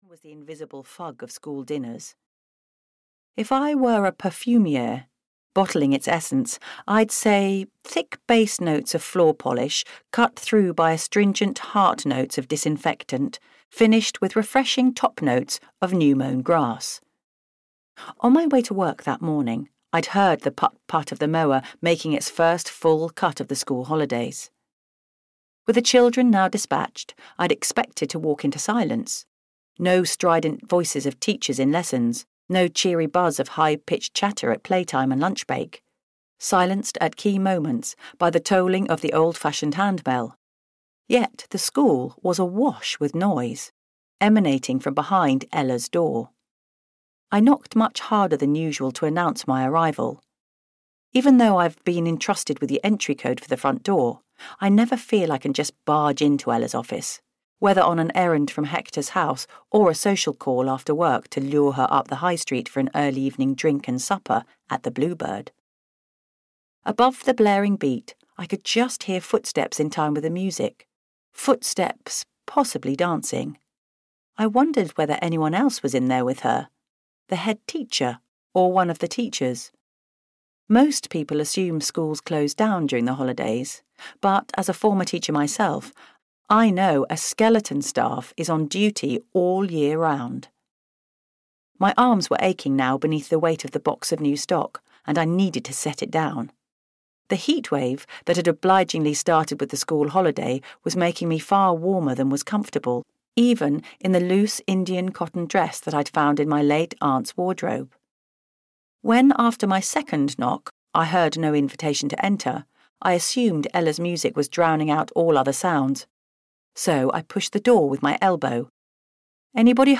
Ukázka z knihy
murder-lost-and-found-en-audiokniha